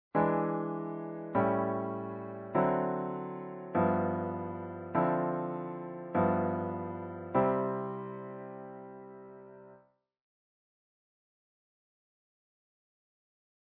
In this next idea, we'll cycle V7 chords by the interval of a perfect fourth. This forms the common harmonic cycle or 'bridge' section of what jazz players know as rhythm changes.